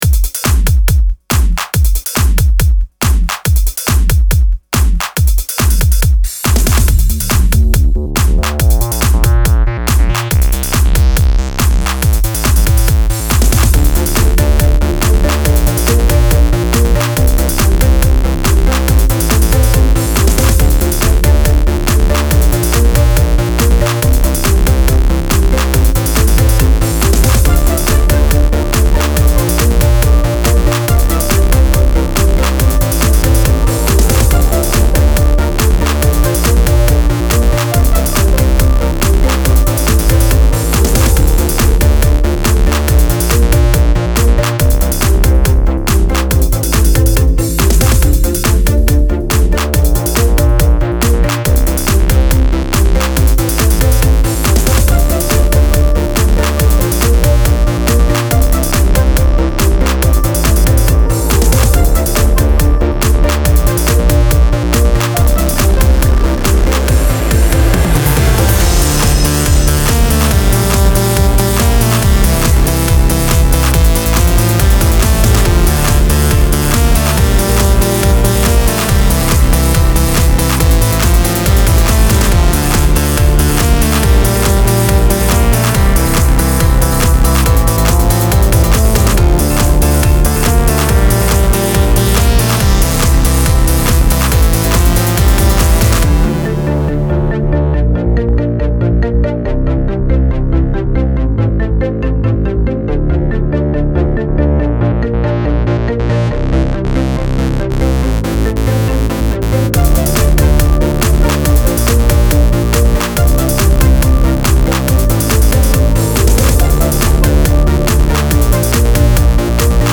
Style Style EDM/Electronic
Mood Mood Aggressive, Driving, Epic +1 more
Featured Featured Bass, Choir, Drums +2 more
BPM BPM 140